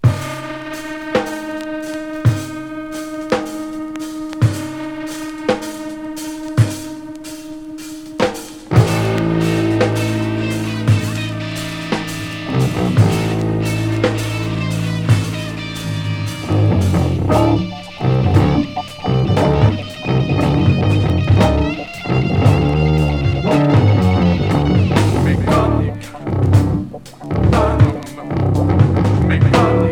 Free rock Quatrième 45t retour à l'accueil